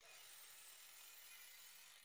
SD_SFX_GrapplingHook_Pull_Loop.wav